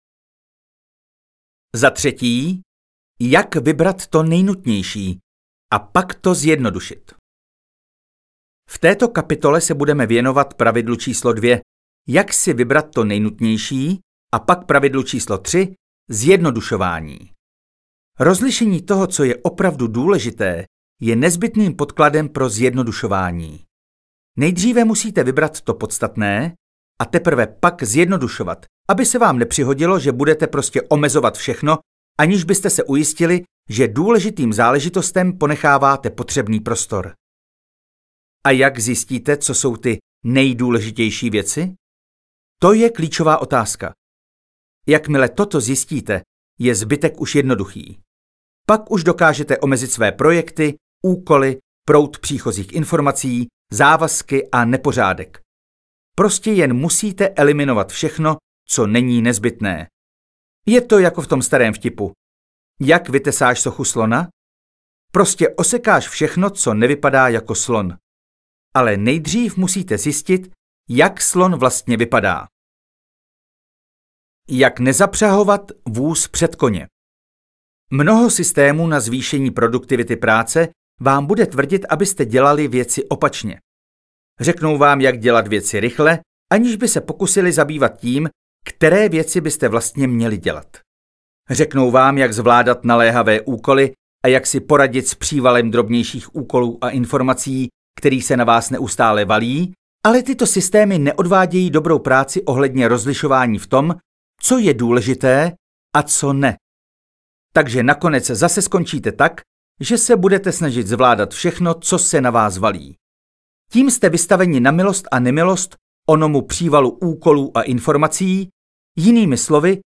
Audiokniha Síla jednoduchosti - Leo Babauta | ProgresGuru
audiokniha